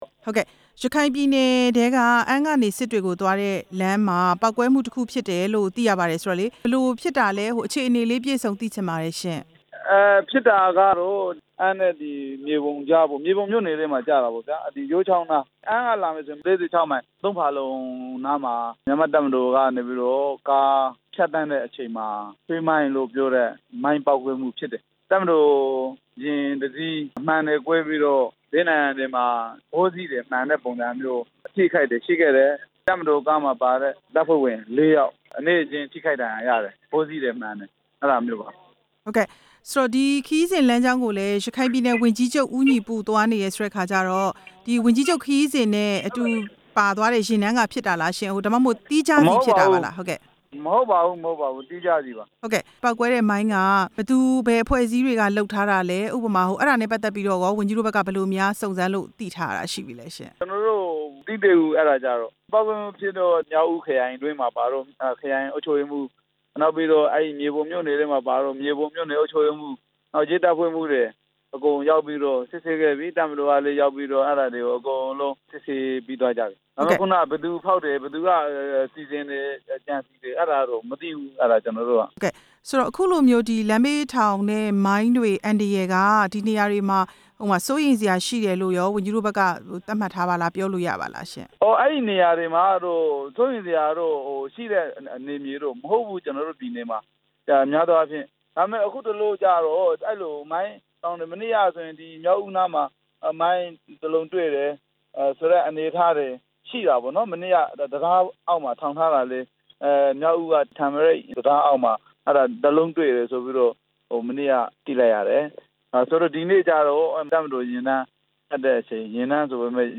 မြေပုံမြို့နယ်မှာ ဆွဲမိုင်းပေါက်ကွဲမှု မေးမြန်းချက်